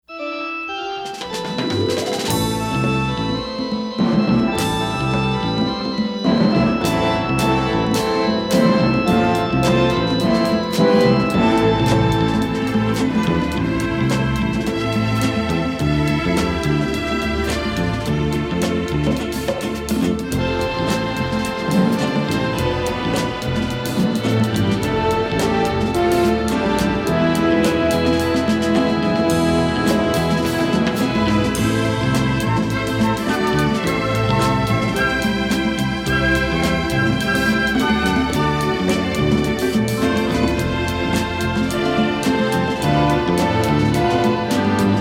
beautiful pop-flavored score